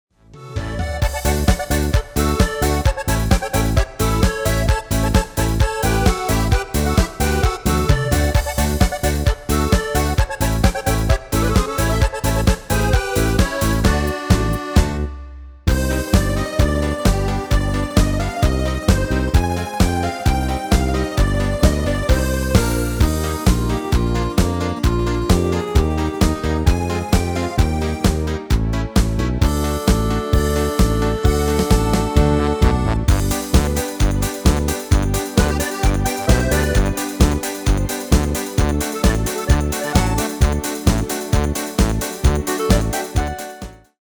Demo's zijn eigen opnames van onze digitale arrangementen.